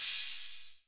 DDWV OPEN HAT 5.wav